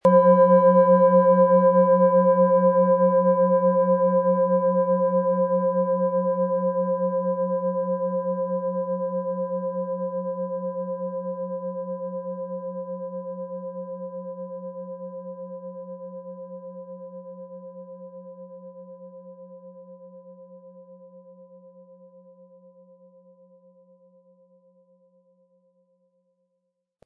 Planetenschale® antik Gestärkt werden & Energetisch sein mit DNA-Ton, Ø 15,8 cm, 320-400 Gramm inkl. Klöppel
Die DNA-Frequenz (528 Hz):
Diese antike Klangschale hat an der Oberfläche Patina oder Altersflecken, das den Klang in keinster Weise beeinträchtigt.